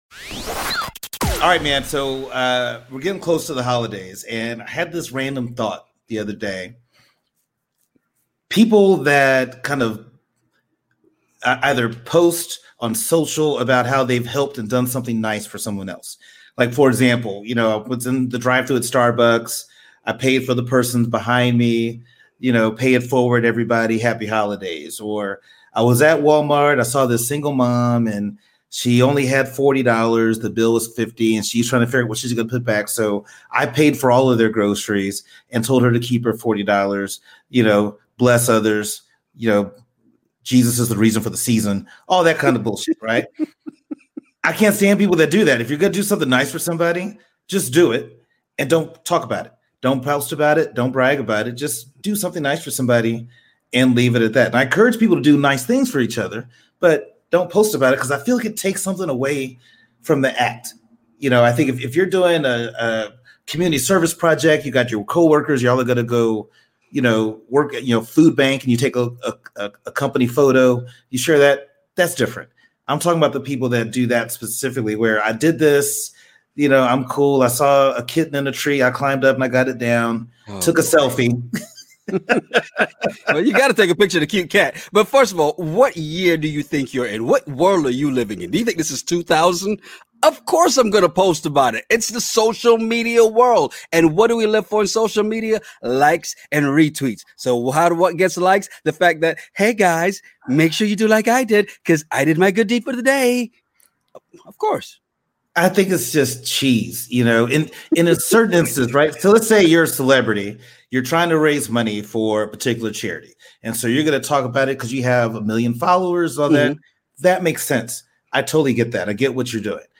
Ever been somewhere and overheard two guys having a crazy conversation over random topics?